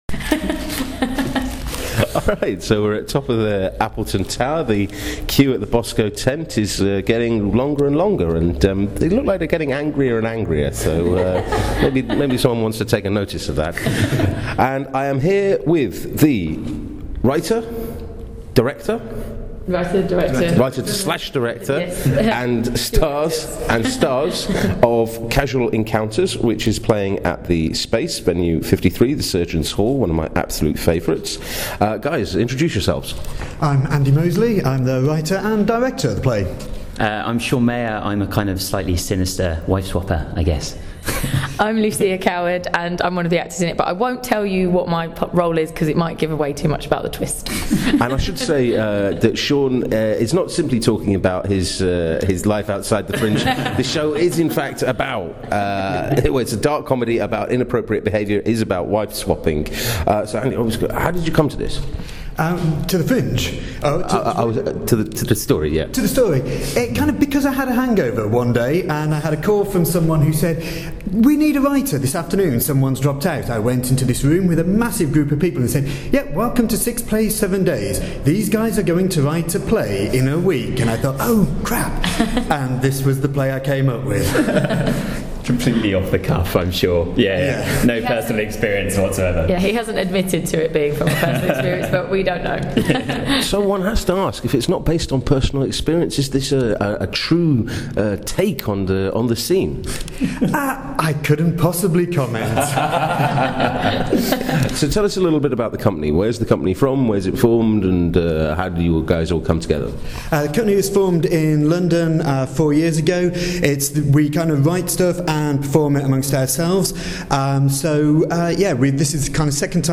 talks to the team behind Casual Encounters.